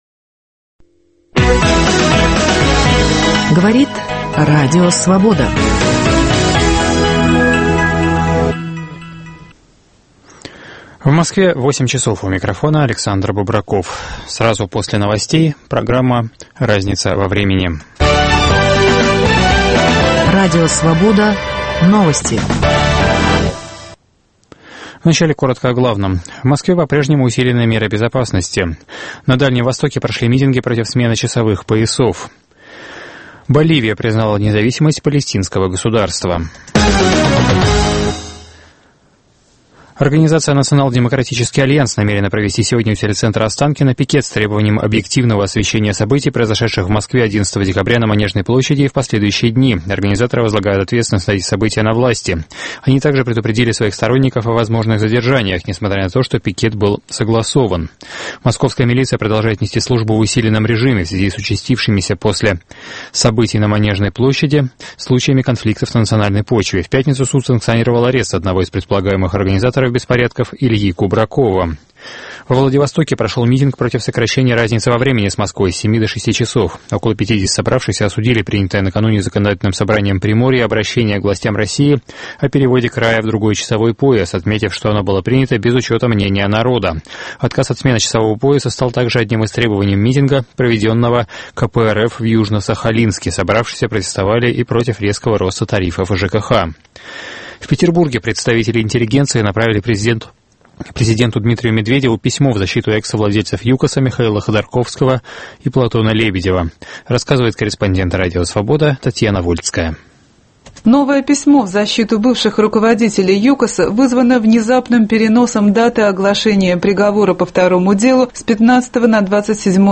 В программе очередная передача цикла "Как у Дюма..." - историк и писатель Натан Эйдельман рассказывает в Сорбонне (1988 г.) о тогдашних проблемах изучения российско-советского прошлого